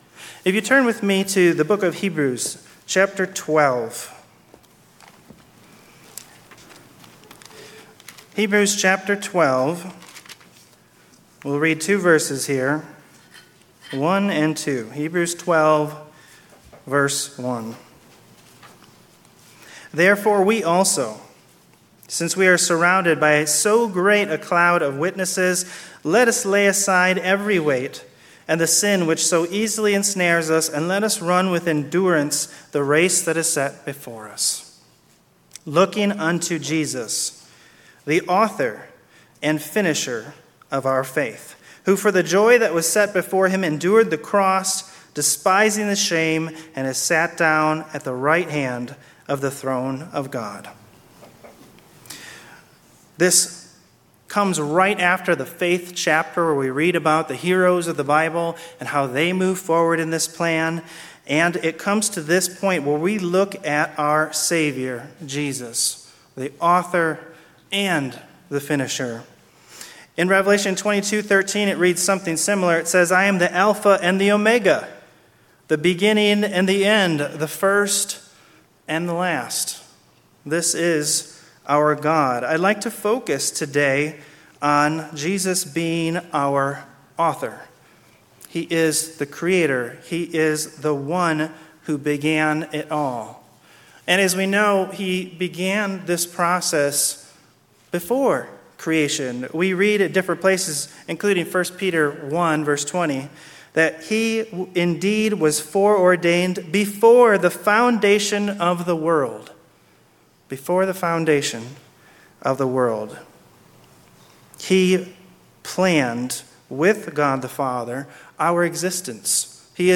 Sermons
Given in Klamath Falls, Oregon